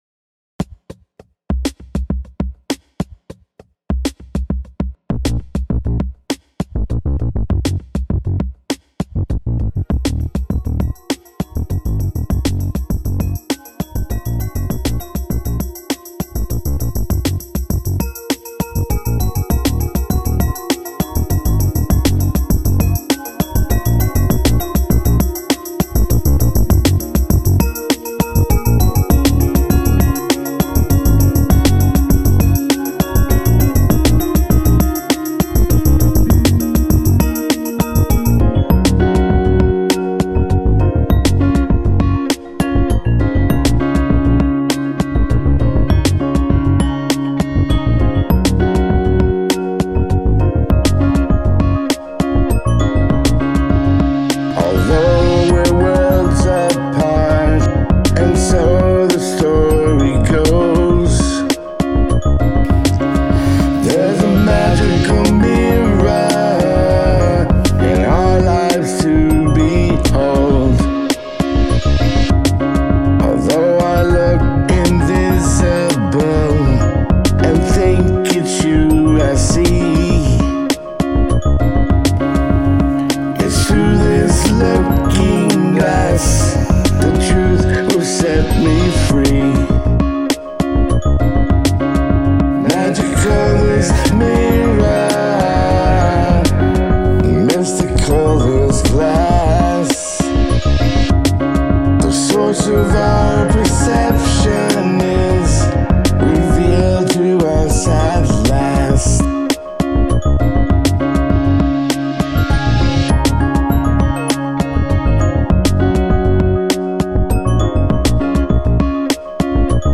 A funky jam.
Categories: 2022Pop